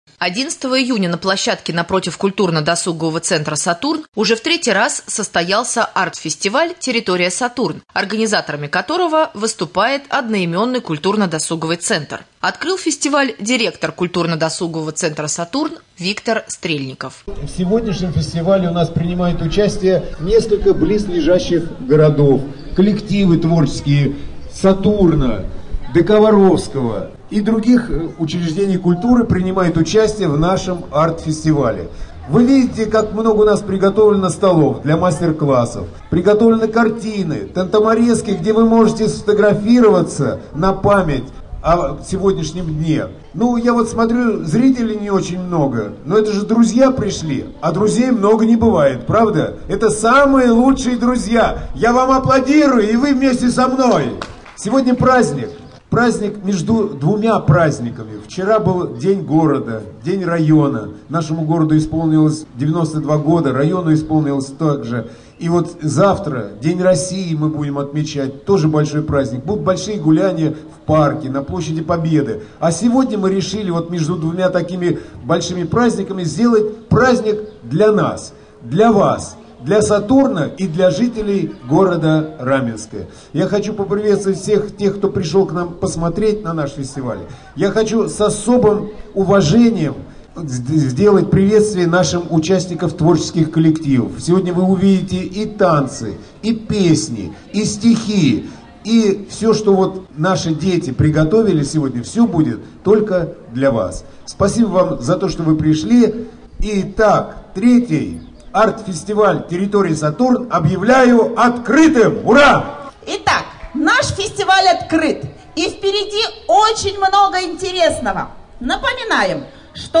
Репортаж с арт-фестиваля «Территория Сатурн»